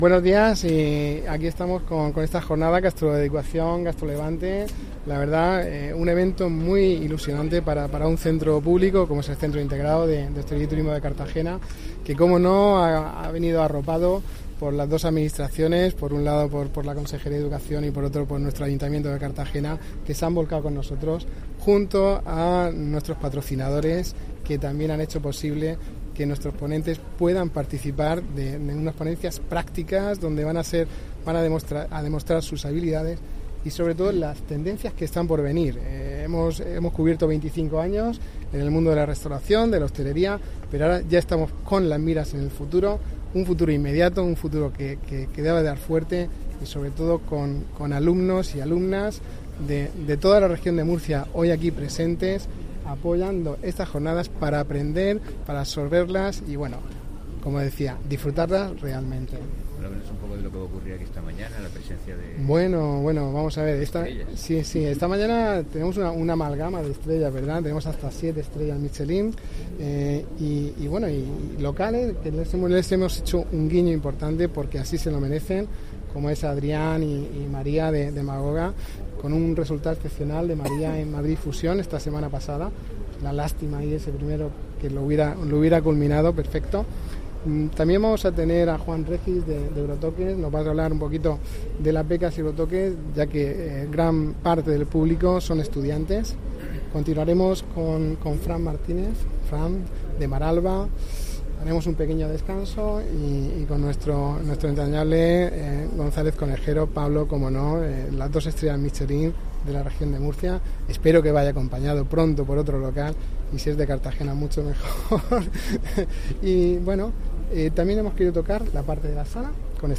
Audio: Declaraciones Jornadas Gastroeducaci�n y Gastrolevante en El Batel (MP3 - 4,75 MB)